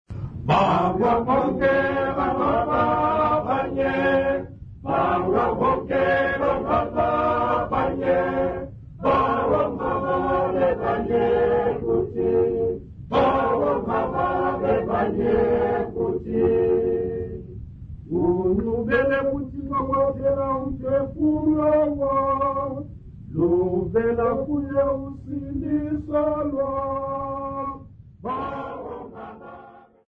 Workshop participants
Church music South Africa
Hymns, Xhosa South Africa
Folk music South Africa
Africa South Africa Zwelitsha, Eastern Cape sa
field recordings
Xhosa composition workshop.